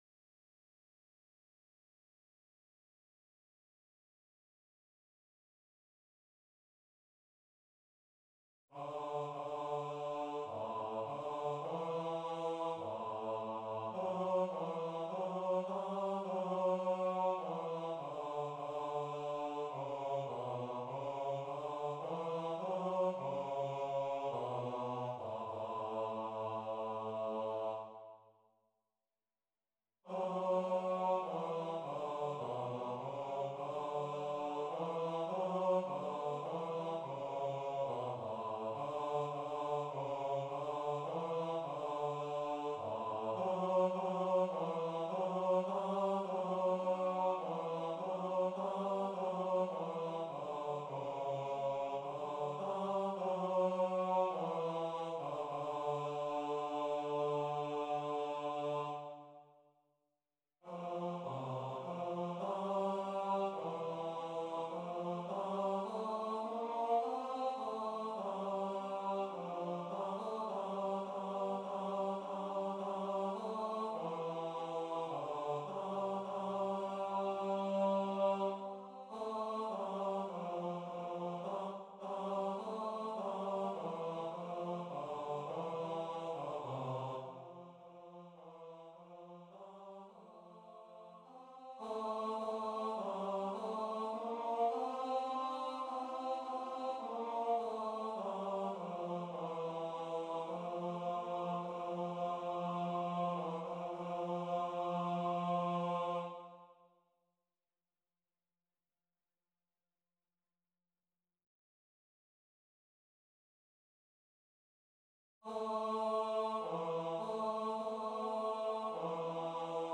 This page is for rehearsal purposes only…
TTBB
Baritone
O-Come-All-Ye-Faithful-Forrest-TTBB-Bar.mp3